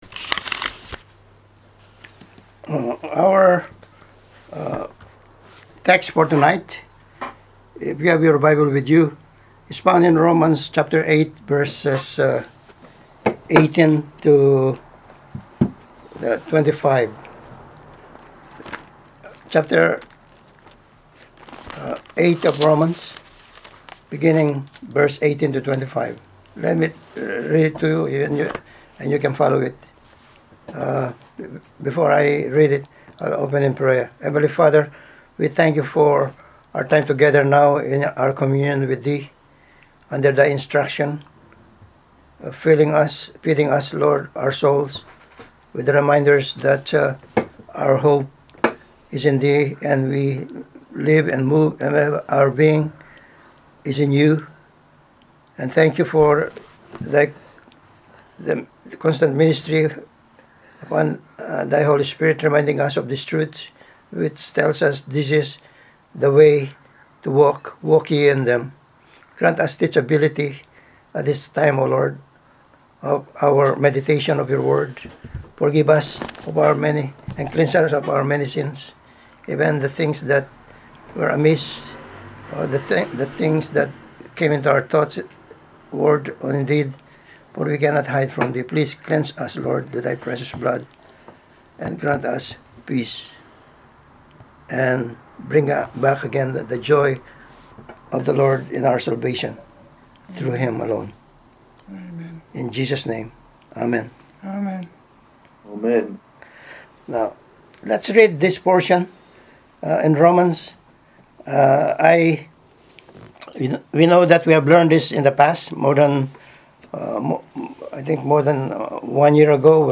Passage: Romans 8:17-25 Service Type: Wednesday Bible Study « “If any man thirst